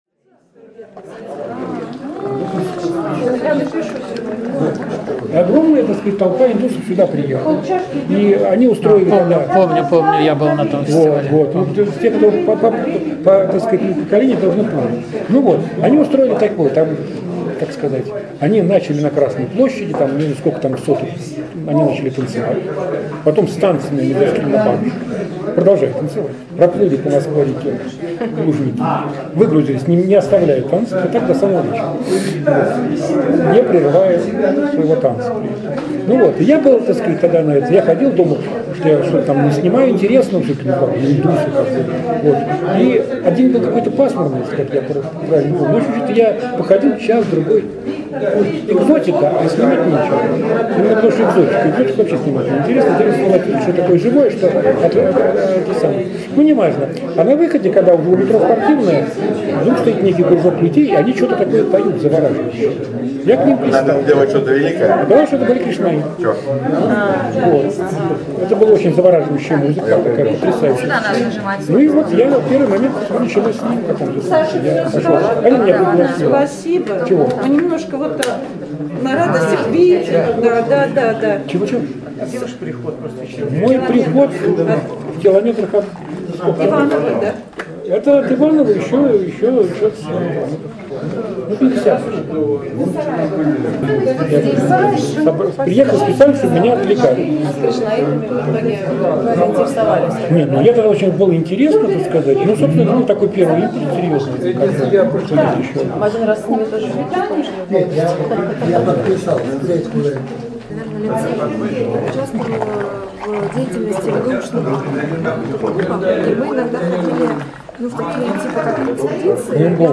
прошла 22 января 2020 года в библиотеке имени Лермонтова